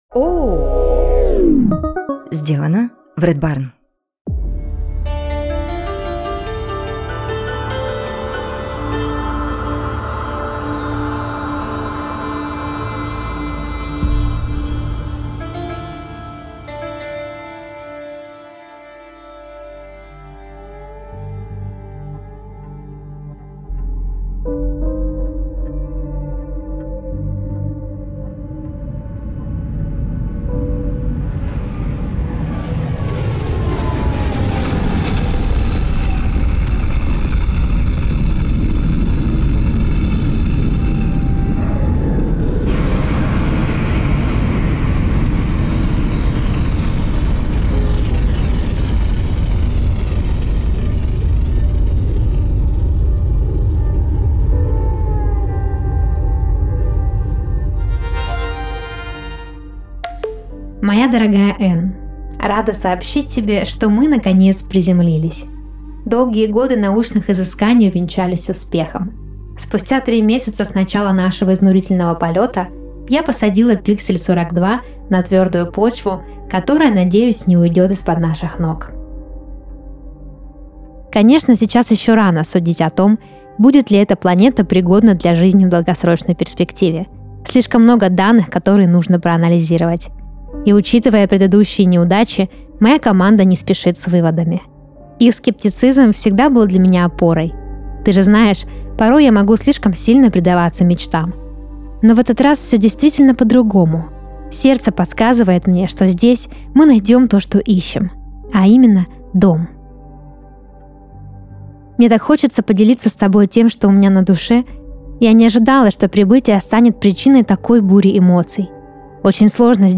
Каждый выпуск слушатели присылают нам свои сны, а мы переделываем их в художественный рассказ и зачитываем под расслабляющую музыку.